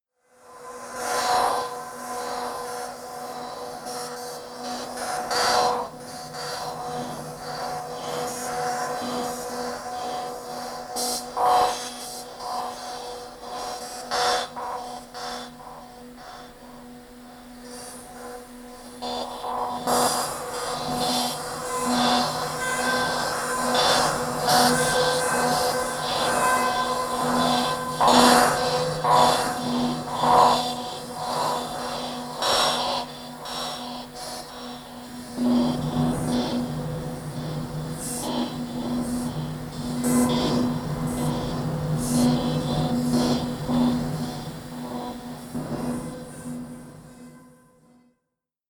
Sound Art Series